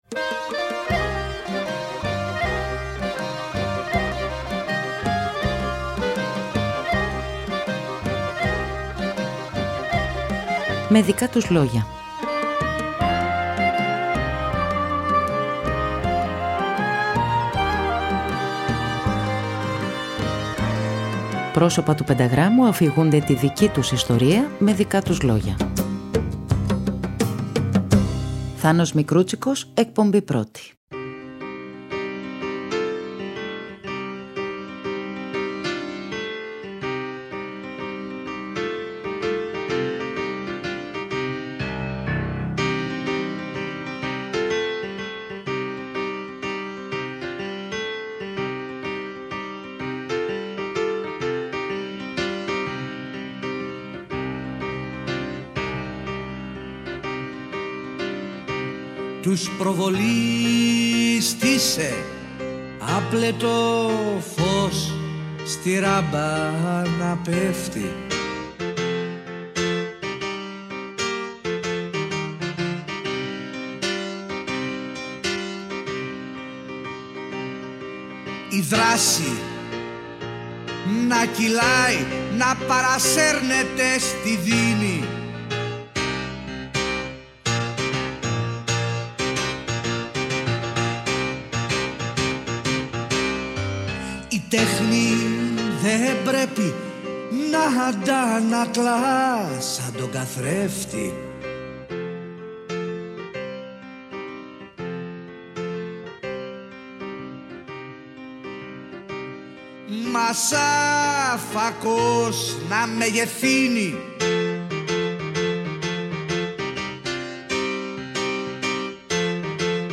Η άλλη πλευρά αυτή του συνθέτη λόγιας μουσικής, ο ποιητής Νίκος Καββαδίας και ο “Σταυρός του Νότου”, η κρίση της δισκογραφίας, η κρίση στην Ελλάδα, η κρίση του καπιταλισμού, η εμπλοκή στην πολιτική, οι νεώτερες από τον ίδιο γενιές στο χώρο του ελληνικού τραγουδιού. Από την Κυριακή 20 Μαΐου στις 16.00 το απόγευμα και για τις 3 Κυριακές, σε 3 εκπομπές ο Θάνος Μικρούτσικος αφηγείται τη δική του ιστορία “Με δικά του λόγια” στο Δεύτερο Πρόγραμμα της Ελληνικής Ραδιοφωνίας.